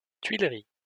-Tuileries.wav Audio pronunciation file from the Lingua Libre project.